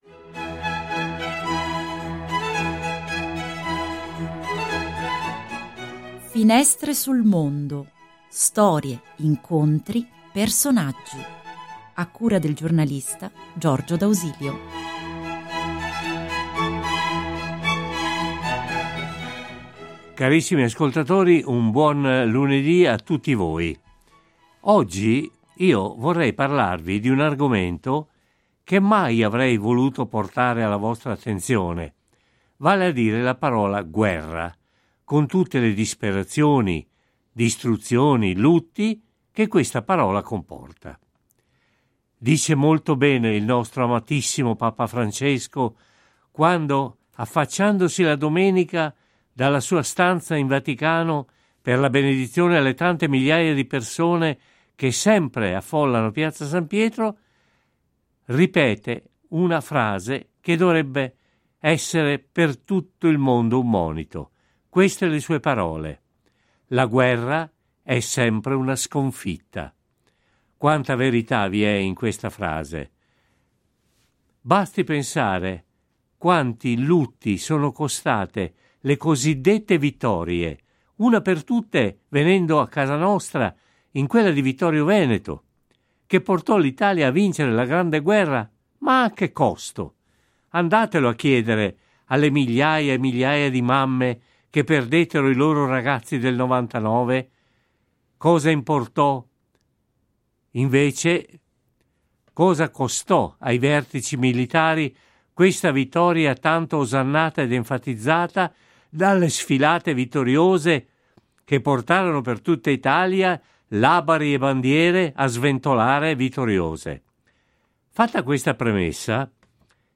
Intervista su Radio Oreb sulle cause delle Guerre.
Lunedì 24 marzo su Radio Oreb è andata in onda una puntata sulle cause delle guerre nel periodo contemporaneo.